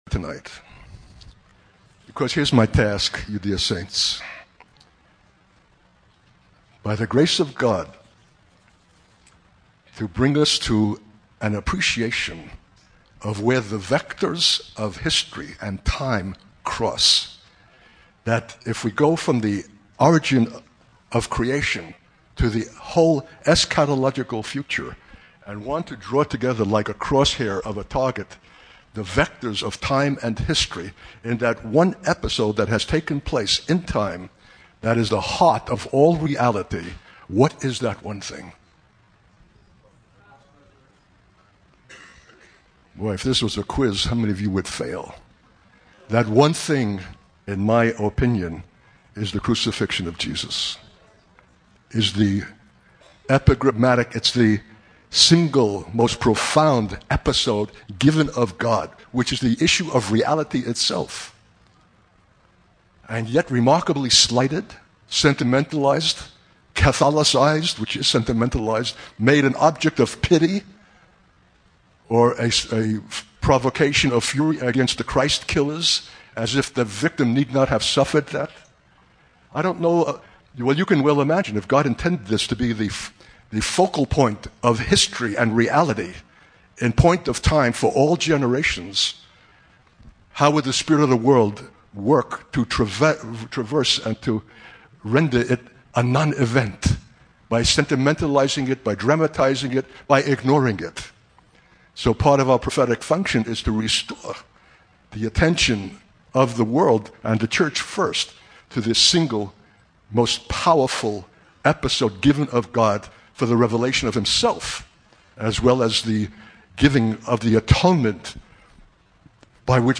In this sermon, the preacher emphasizes the crucifixion of Jesus as the most profound episode in history that reveals the heart of all reality. He suggests that the crucifixion is not only significant for Jesus but also for Israel's future experience. The preacher predicts a time when Israel will face persecution and destruction, with many being astonished at their suffering.